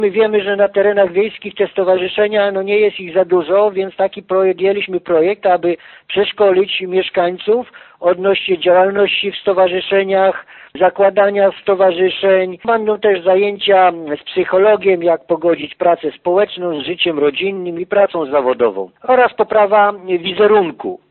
Najnowszy „Trzydnik Duży – tu mieszka inicjatywa i integracja”to propozycja dla społeczników – mówi wójt Franciszek Kwiecień: